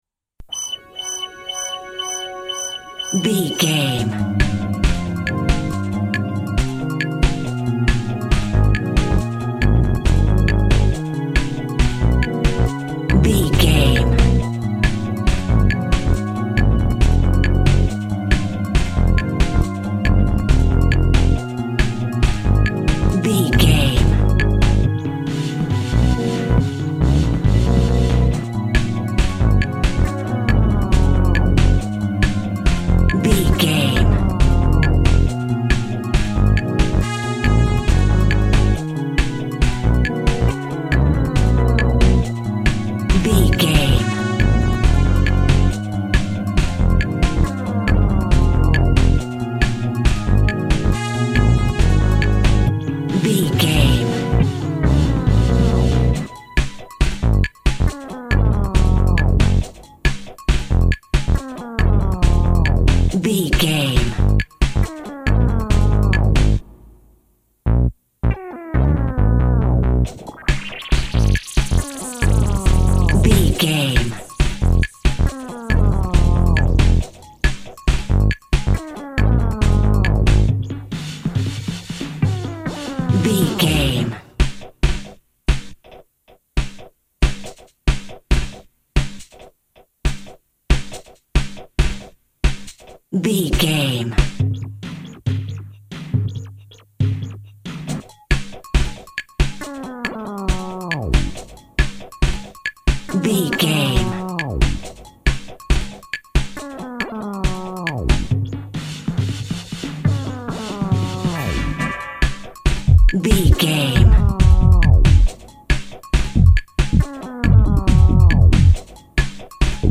Aeolian/Minor
G#
Slow
futuristic
hypnotic
mechanical
dreamy
meditative
drum machine
brass
synthesiser
percussion
electronic
synth lead
synth bass
Synth pads